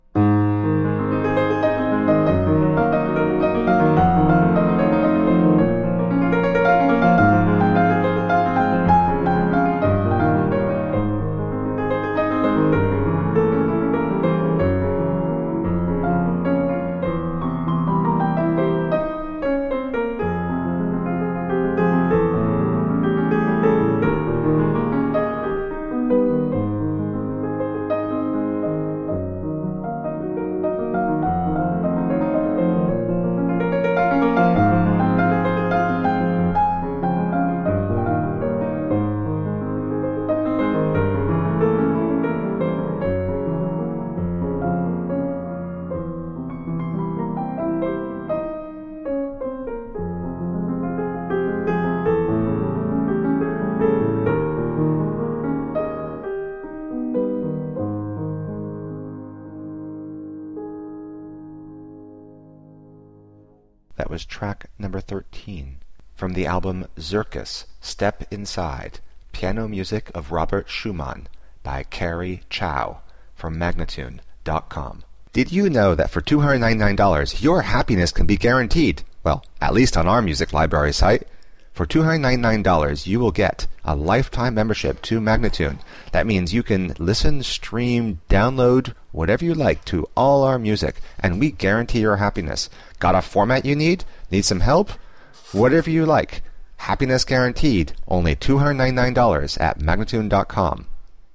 Virtuoso piano.